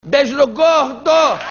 Apresentador Jô Soares se despede do Programa do Jô.